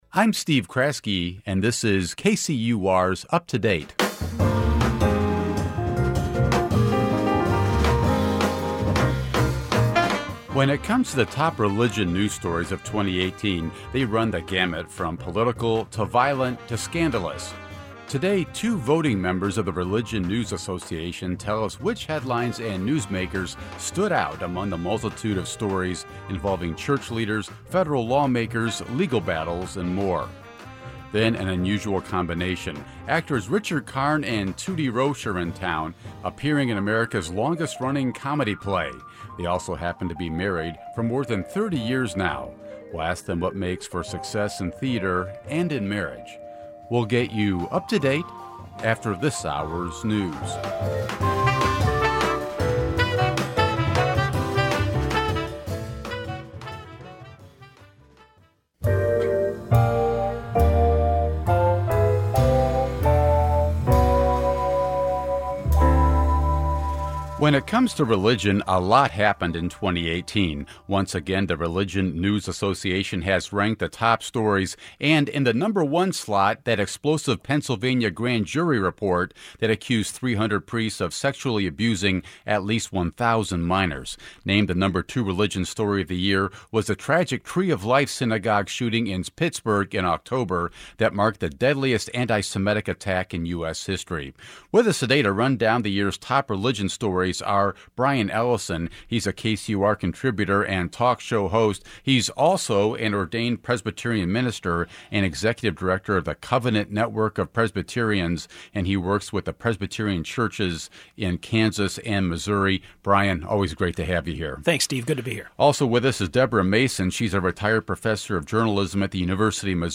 The passing of Rev. Billy Graham, the Pennsylvania priest sexual abuse scandal and the mass shooting at the Tree of Life synagogue in Pittsburgh are examples of the top religion stories of the past year. Two members of the Religion Newswriters Association explained why these and others made the association's list.
The actors talked about how they met, their past roles, what it's like being married in the industry, and how they deal with a play that can have a different ending with every performance.